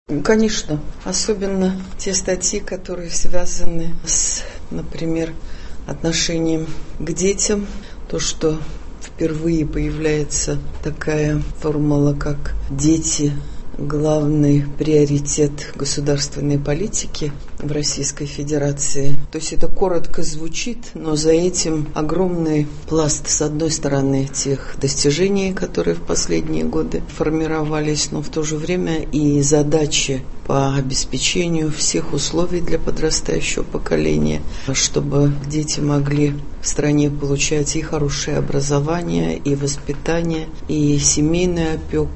О важности вносимых изменений рассказала корреспонденту ГТРК «Татарстан» Председатель Общественной палаты РТ Зиля Валеева: